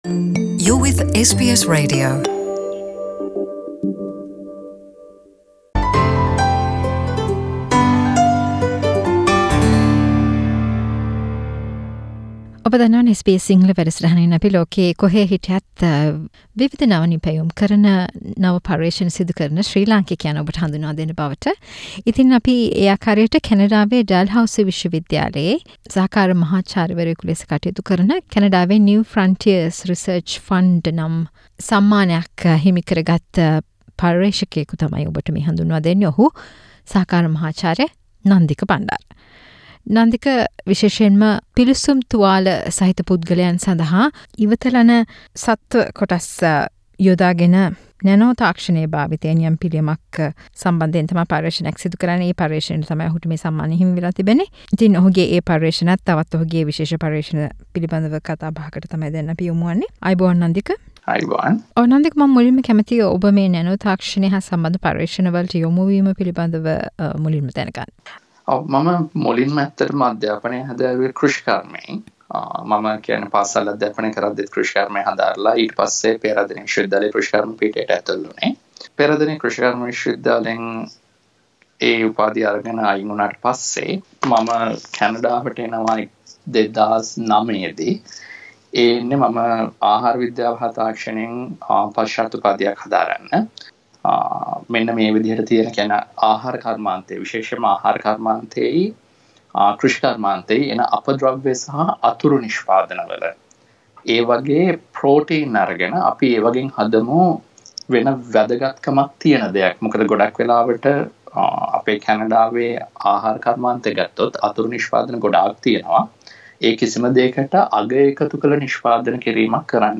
SBS සිංහල සේවය සිදු කල පිළිසඳර